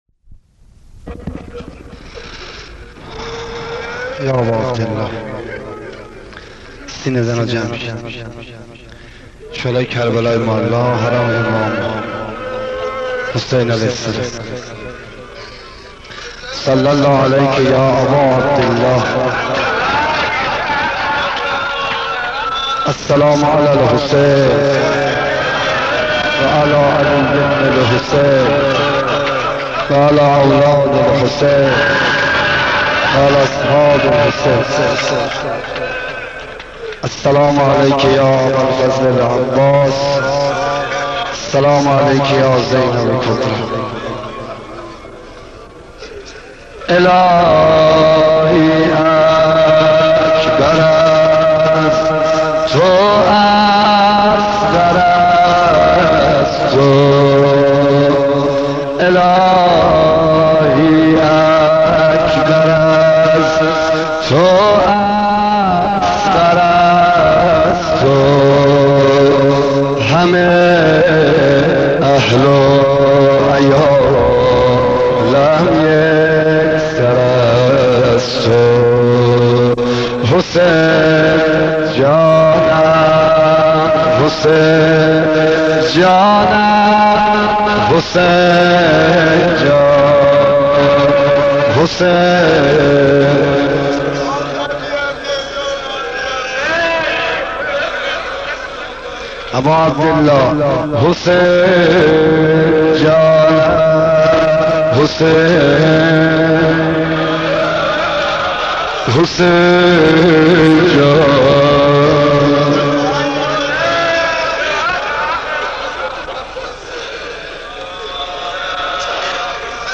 در هیئت عشاق‌الحسین(ع) تهران
مداحی